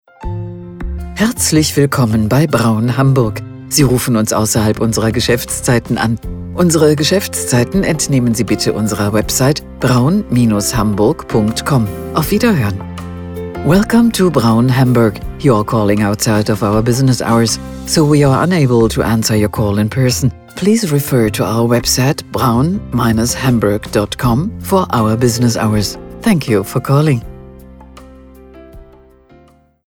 Telefonansagen mit echten Stimmen – keine KI !!!